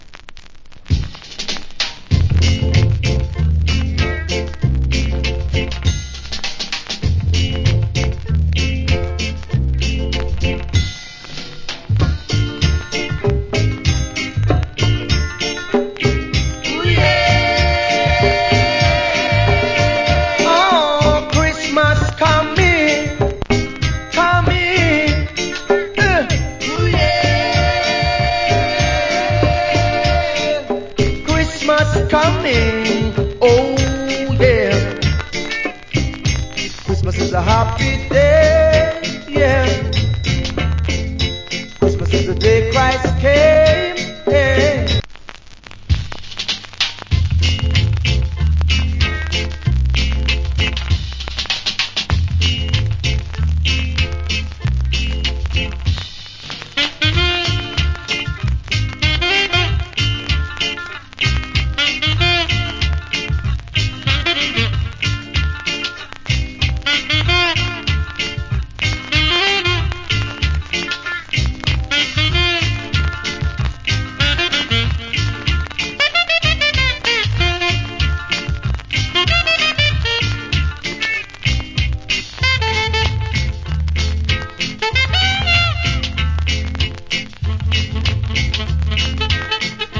Good Chrismas Early Reggae Vocal.